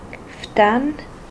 [ftan]/?, deutsch und bis 1943 offiziell Fetan) ist ein Dorf in der Unterengadiner Gemeinde Scuol im Schweizer Kanton Graubünden.
Roh-vallader-Ftan.ogg.mp3